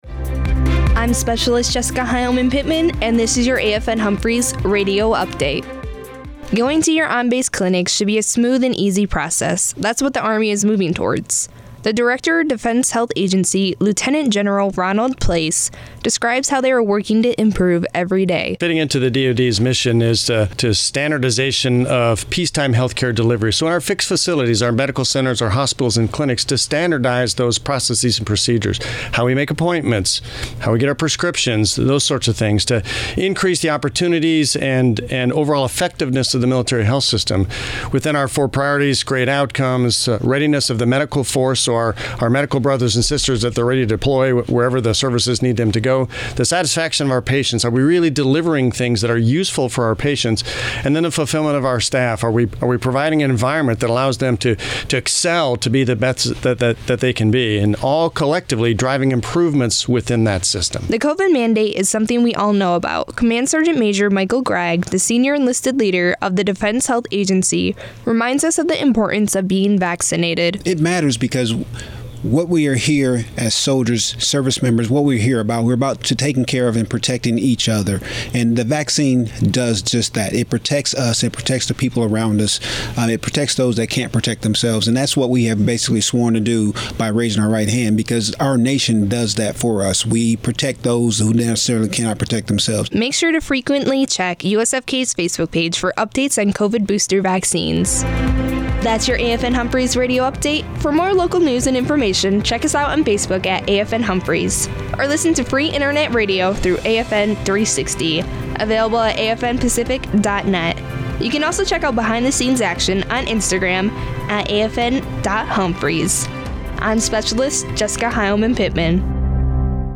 Radio newscast highlighting updates from LTG Ronald Place updating on changes to the Defense Healthcare System